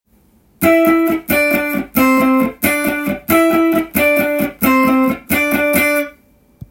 オクターブ奏法でAmペンタトニックスケールを使用した
譜面通り弾いてみました
TAB譜では３連符のフレーズを集めてみました。